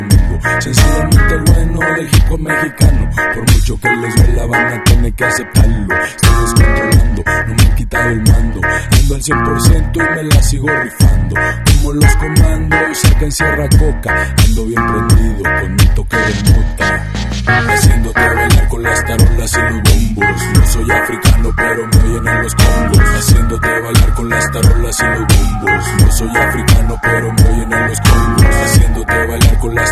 #🔥🔥🔥 #🎧 #🔊 Bombos y sound effects free download
Bombos y tarolas